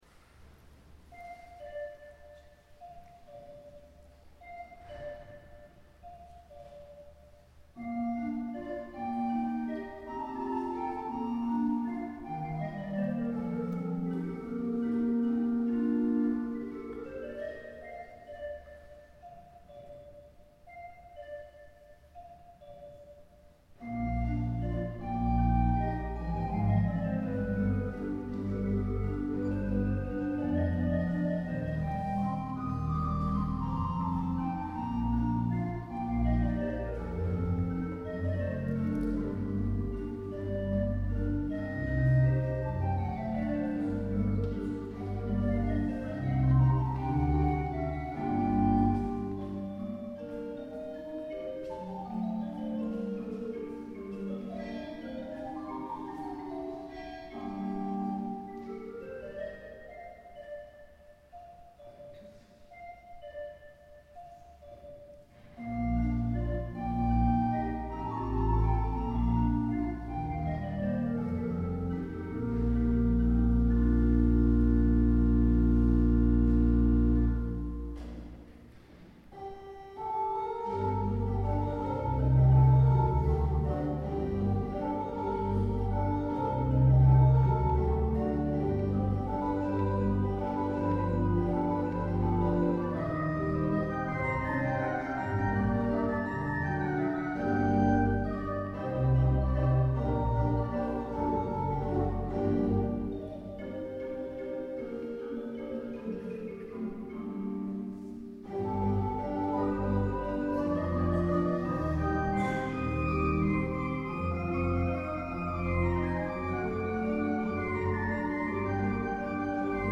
Concierto celebrado en Collbató del 50º aniversario del Órgano del Sol Mayor de Marbella.